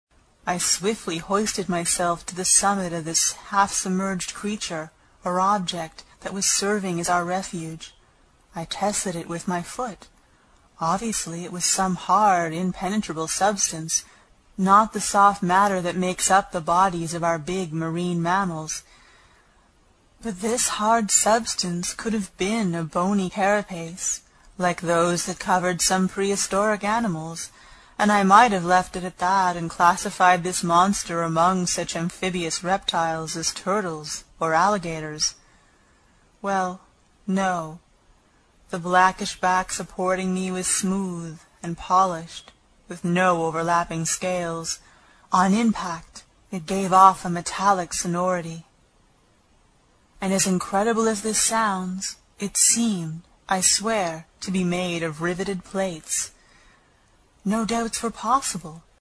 英语听书《海底两万里》第89期 第7章 一种从未见过的鱼(12) 听力文件下载—在线英语听力室